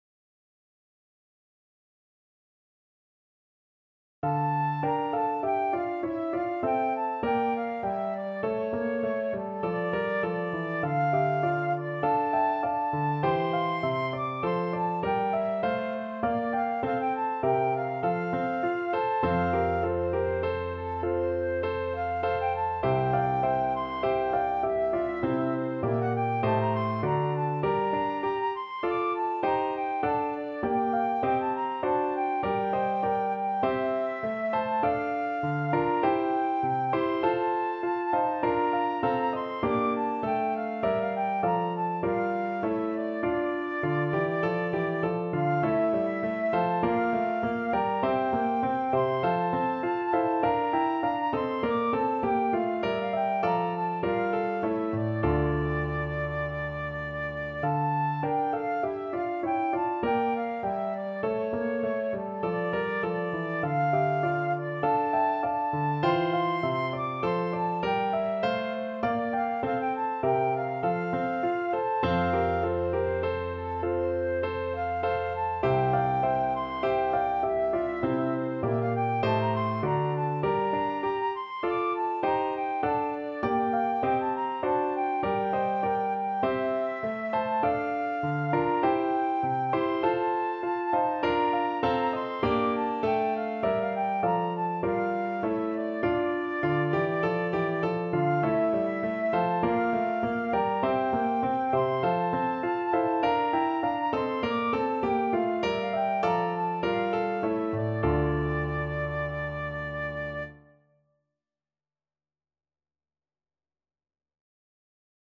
Play (or use space bar on your keyboard) Pause Music Playalong - Piano Accompaniment Playalong Band Accompaniment not yet available reset tempo print settings full screen
D minor (Sounding Pitch) (View more D minor Music for Flute )
4/4 (View more 4/4 Music)
Classical (View more Classical Flute Music)